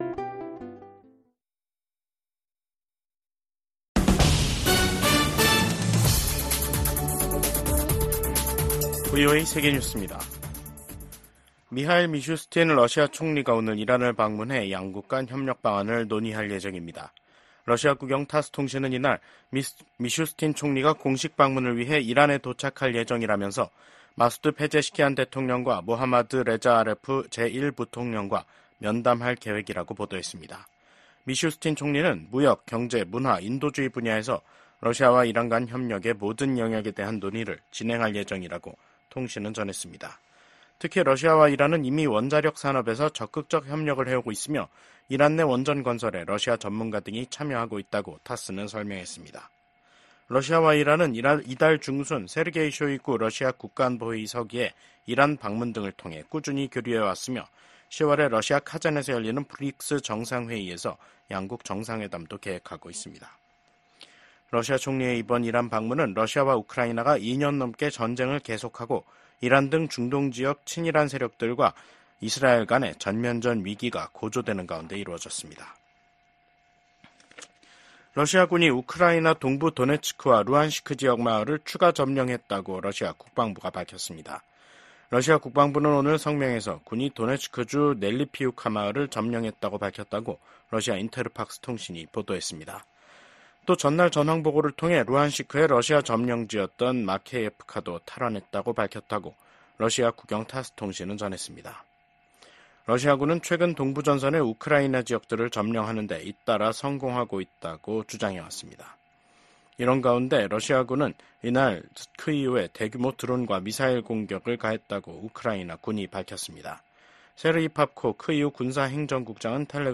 VOA 한국어 간판 뉴스 프로그램 '뉴스 투데이', 2024년 9월 30일 2부 방송입니다. 미국 국무장관이 북한을 압박해 러시아에 대한 무기 제공을 중단시켜야 한다고 강조했습니다. 미 국무부는 북한이 사실상 핵보유국이라는 국제원자력기구(IAEA) 수장의 발언과 관련해 한반도의 완전한 비핵화 목표를 다시 한 번 확인했습니다. 한국 정부는 북한 비핵화 목표를 부정한 러시아 외무장관의 발언에 대해 무책임하다고 비판했습니다.